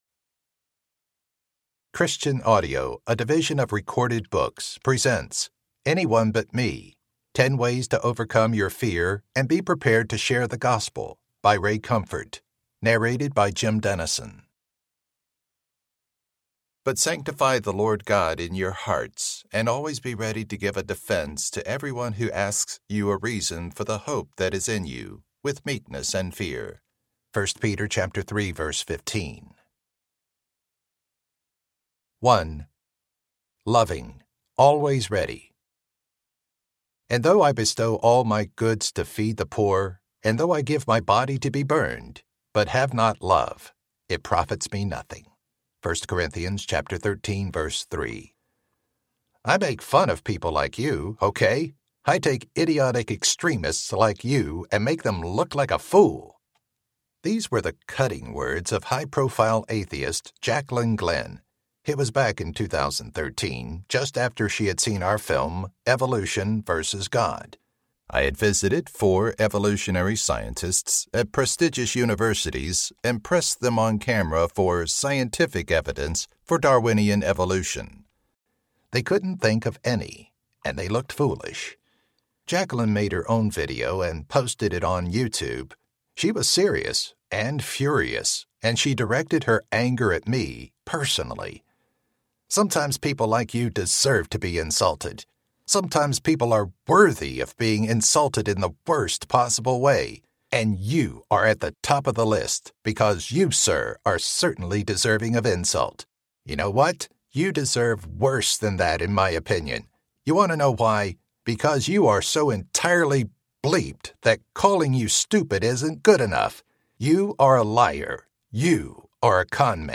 Anyone but Me Audiobook